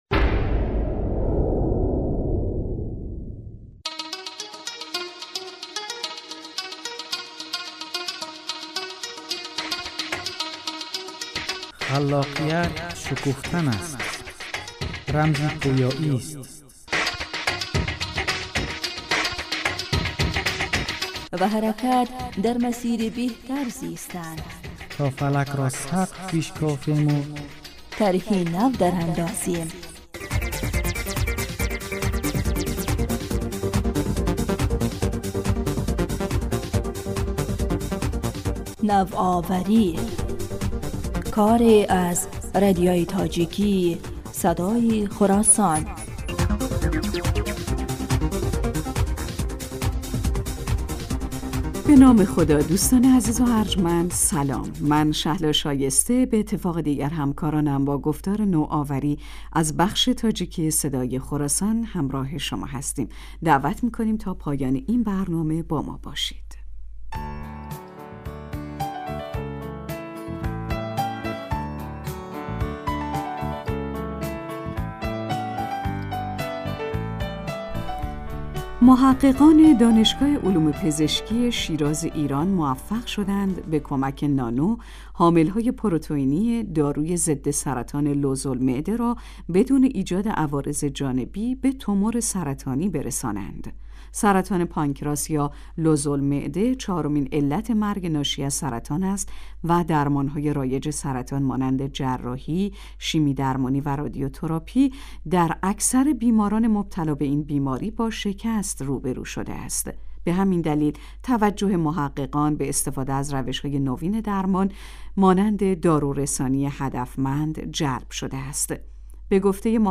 “Навоварӣ” корест аз Радиои тоҷикии Садои Хуросон.